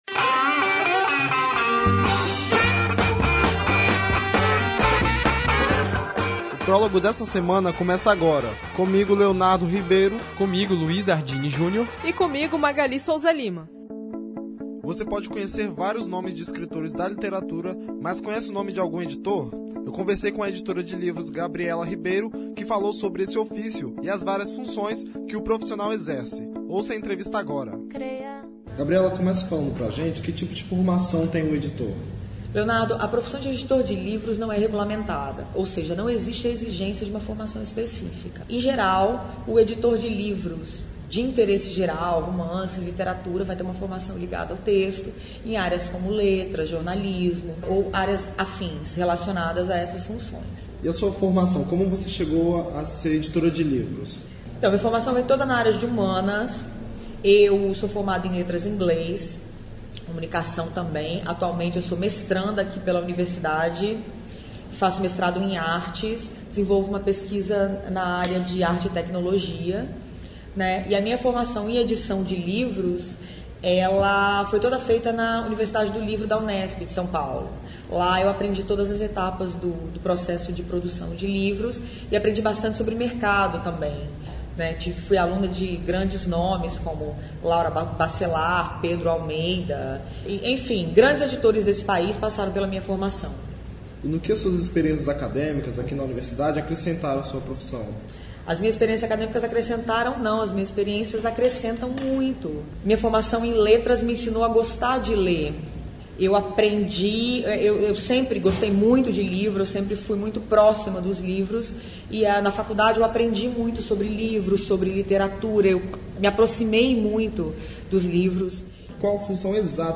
Prólogo entrevista editora de livros | Universitária FM